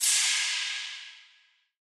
Metro Cymbal [Epic].wav